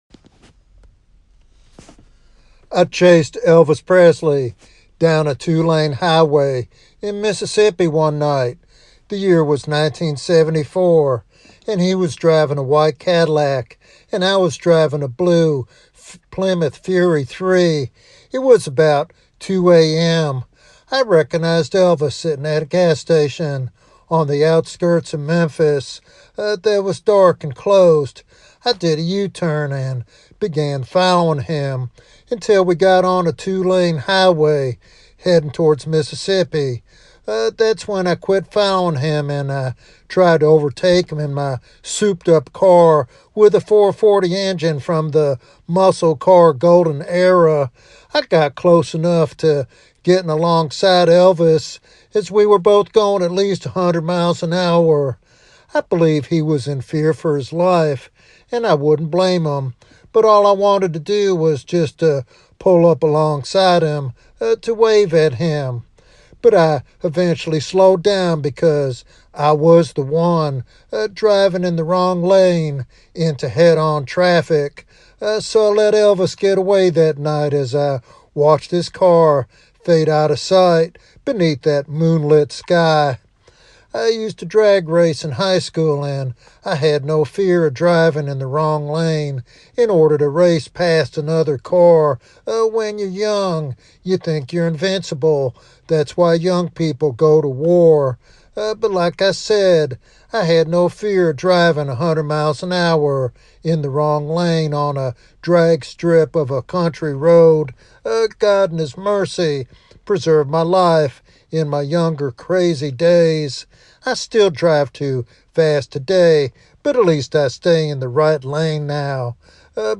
This evangelistic sermon calls for urgent self-examination and a decisive commitment to follow Christ.